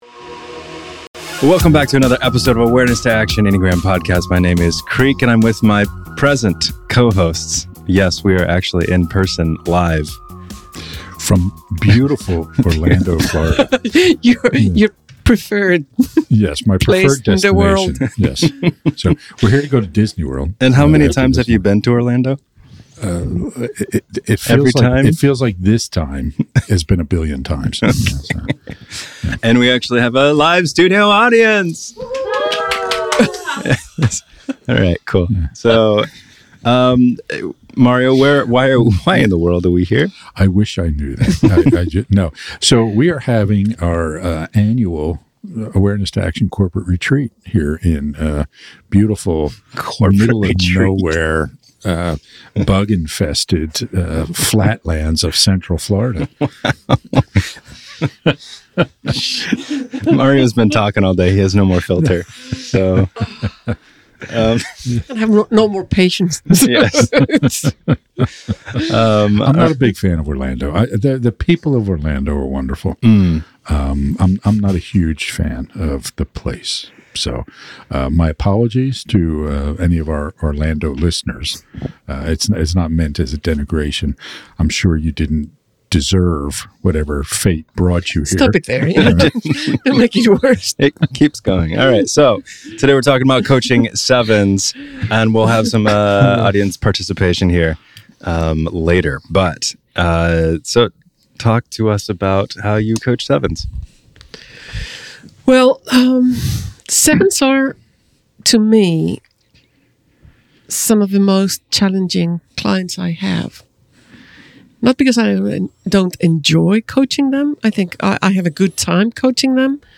The hosts share why it’s easier to work on a vision with a Type Seven rather than address derailers or gaps and other ways they coach them. This episode was recorded during their annual retreat in Orlando, Florida, so they also answer questions from the audience throughout this conversation.